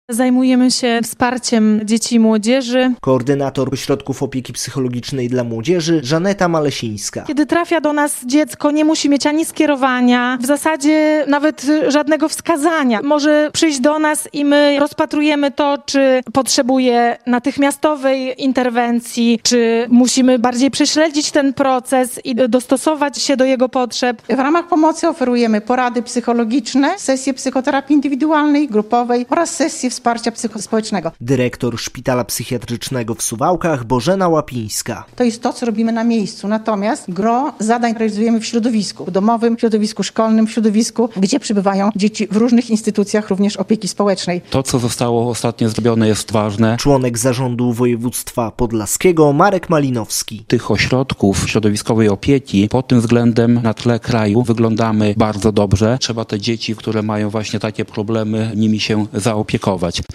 relacja
Młodzi zmagają się z lękami, depresją, zaburzeniami zachowania - podsumowano na konferencji w Sokółce